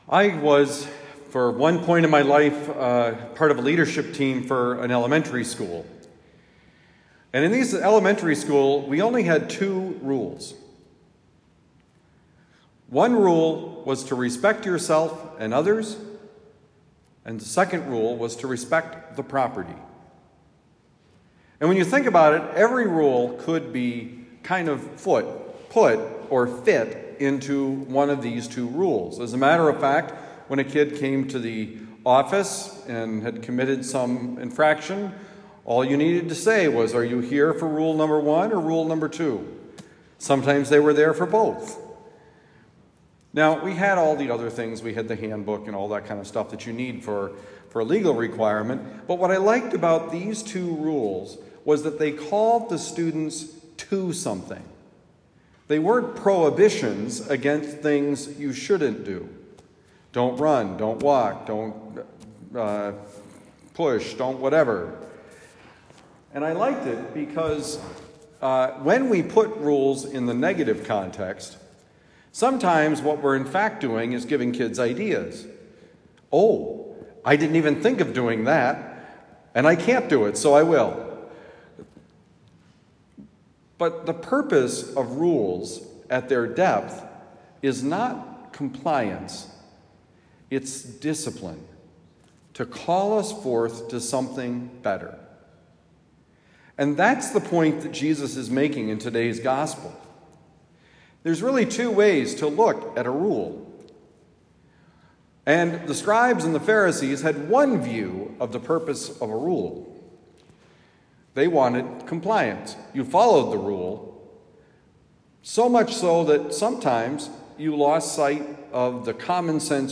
Follow the rules: But in the right way: Homily for Sunday, February 12, 2023
Given at Our Lady of Lourdes, University City, Missouri.